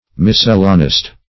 Miscellanist \Mis"cel*la*nist\, n. A writer of miscellanies; miscellanarian.
miscellanist.mp3